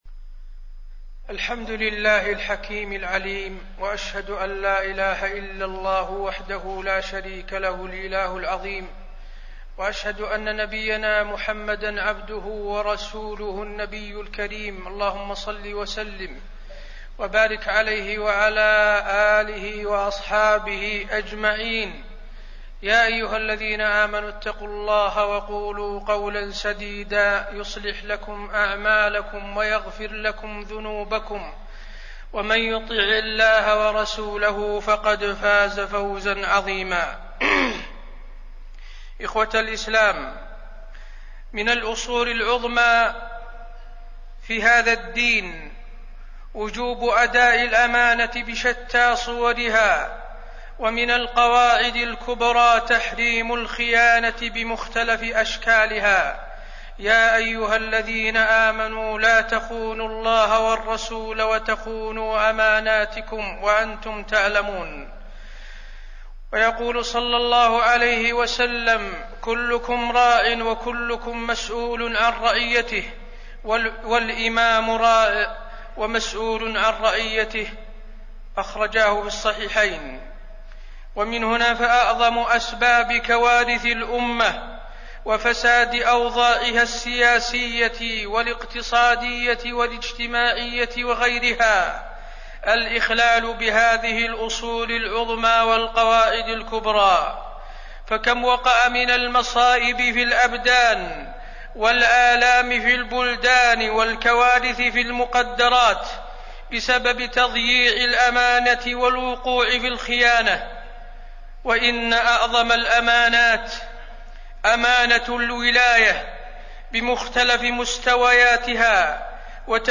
تاريخ النشر ١٥ ربيع الأول ١٤٣٢ هـ المكان: المسجد النبوي الشيخ: فضيلة الشيخ د. حسين بن عبدالعزيز آل الشيخ فضيلة الشيخ د. حسين بن عبدالعزيز آل الشيخ أمانة الولاية وضوابطها